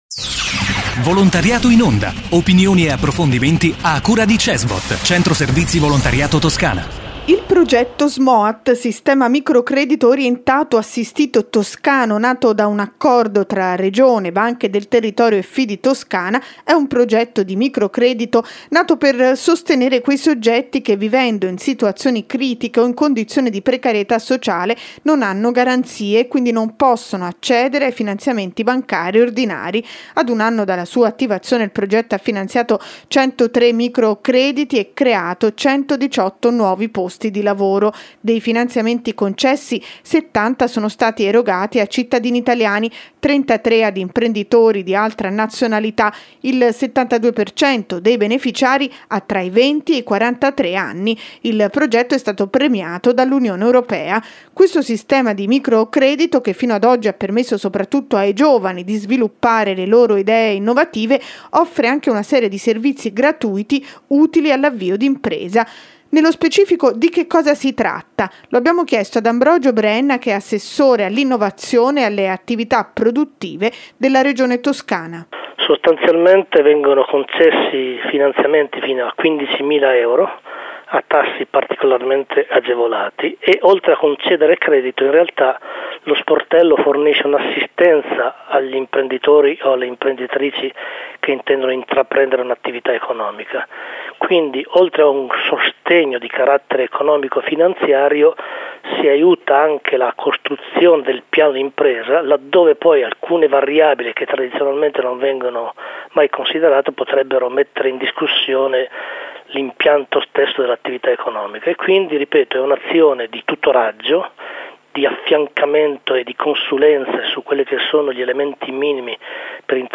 Intervista ad Ambrogio Brenna, assessore all'innovazione e alle attività produttive della Regione Toscana